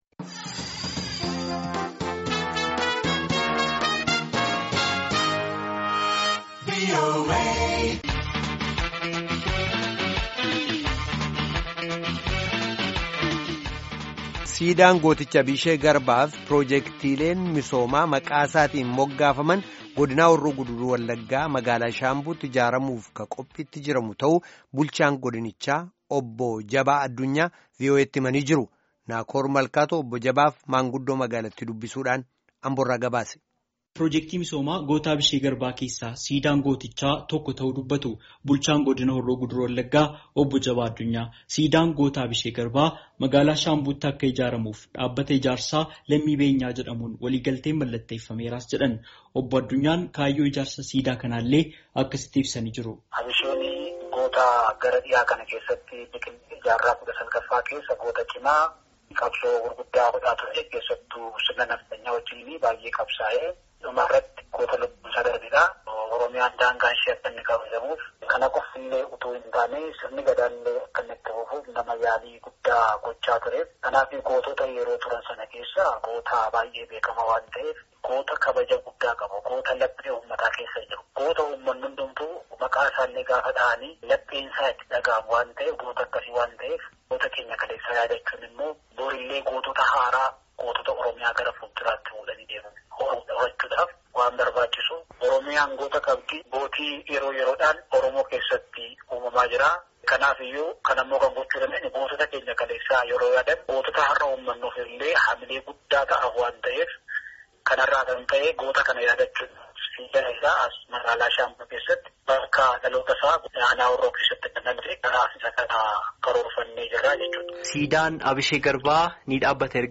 Obbo Jabaa fi maanguddoo magaalattii dubbisuun, Ambo irraa nuuf gabaase.